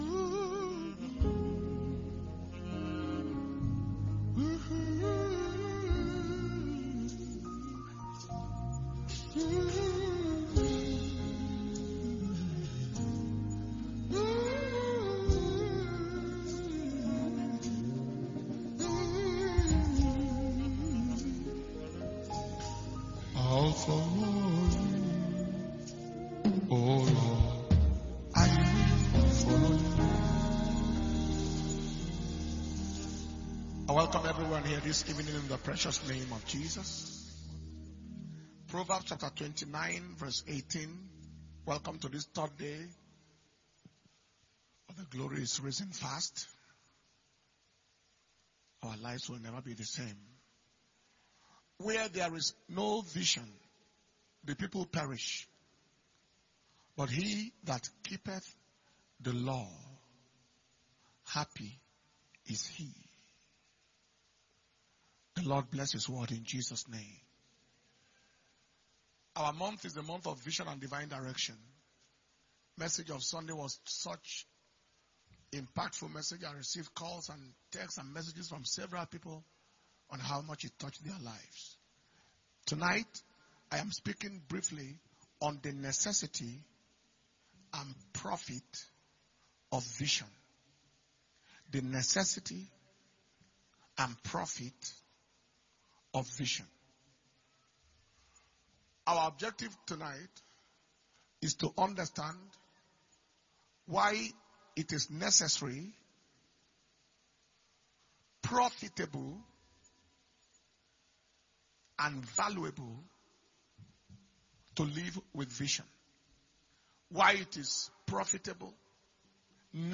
Power Communion Service/2023 The Glory Is Risen Fast – Day 3 – Wednesday, 11th January 2023